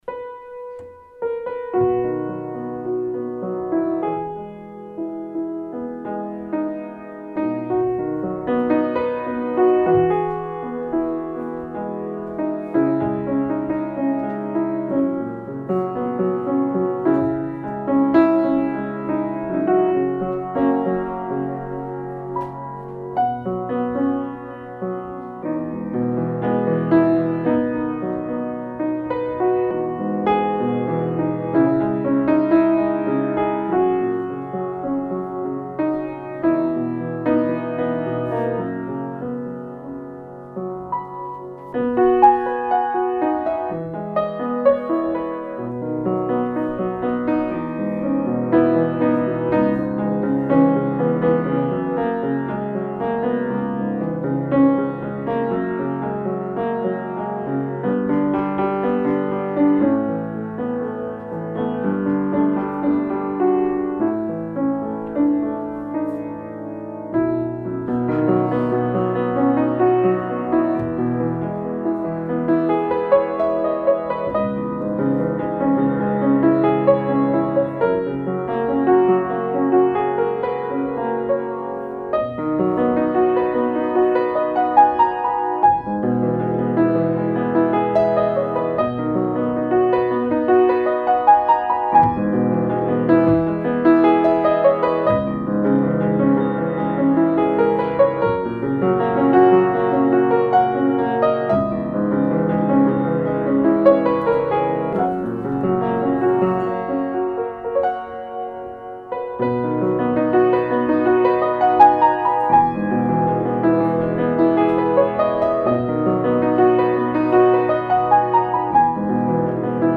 Index of /music/pianoSketches